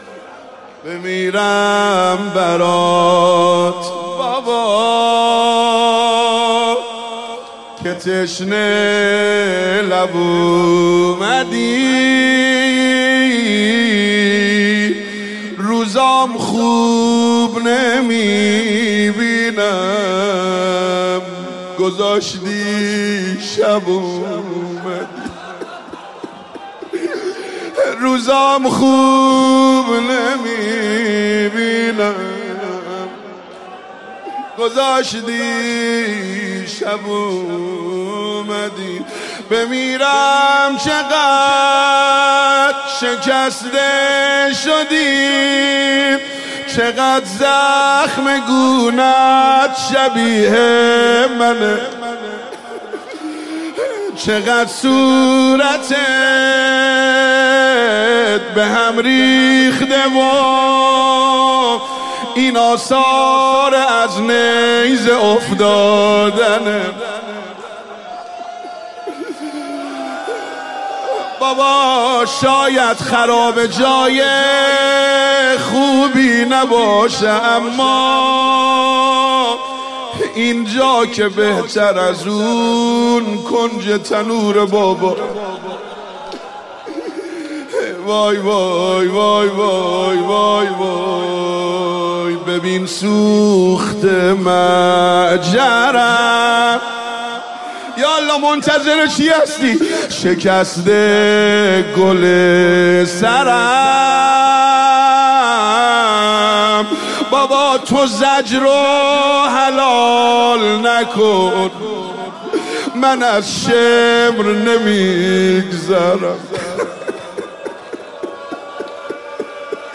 روضه حضرت رقیه سلام الله علیها شب سوم محرم 1399